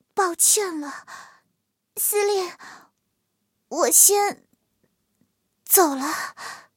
M4谢尔曼被击毁语音.OGG